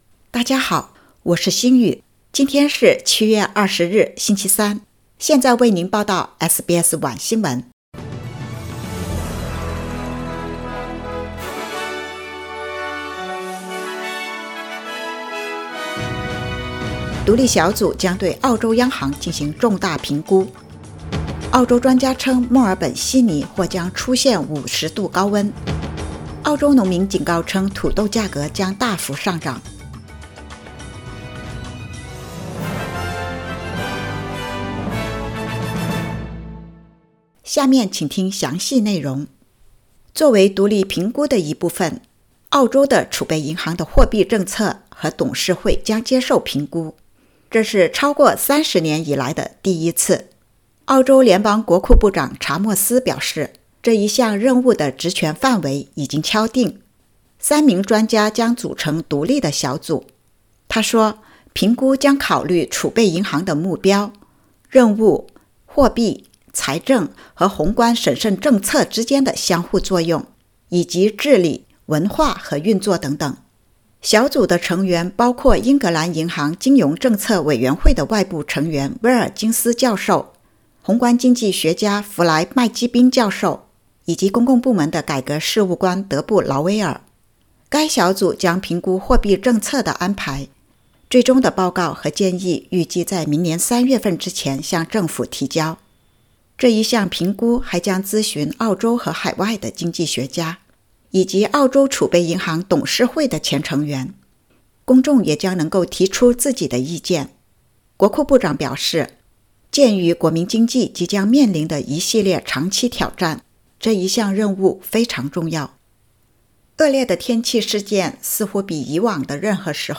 SBS晚新闻（2022年7月20日）
SBS Mandarin evening news Source: Getty Images